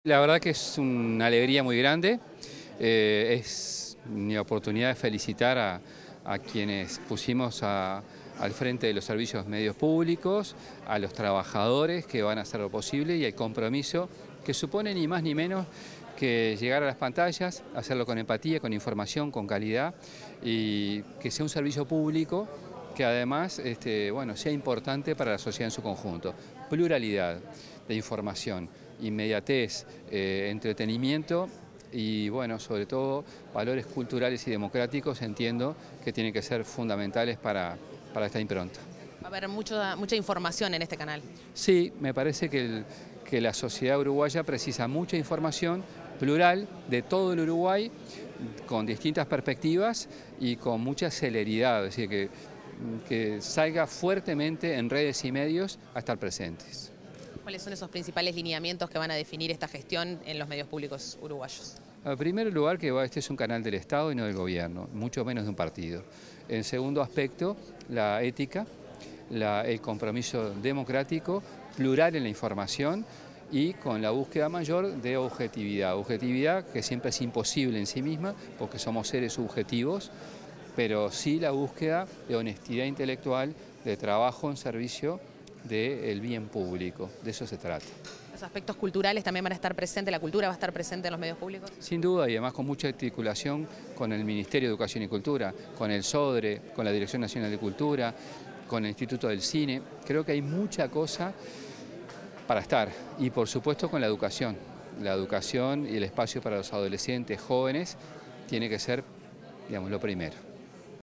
Declaraciones del ministro de Educación y Cultura, José Carlos Mahía
El ministro de Educación y Cultura, José Carlos Mahía, brindó declaraciones, este jueves 8, en la presentación de la programación de la Televisión